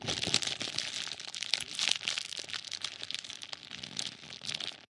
描述：Hojassecándose。 Grabado con ZoomH4n
Tag: 怪异